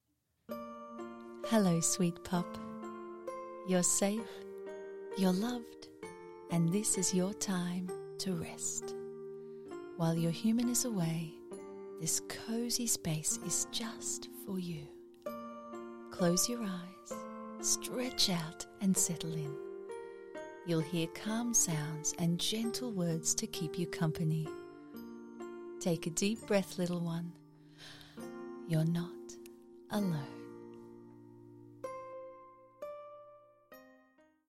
Female
English (Australian)
Narration
Words that describe my voice are Colourful, Friendly, Sincere.